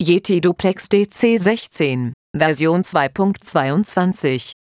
Ich habe mal die Einschaltansage angepasst :)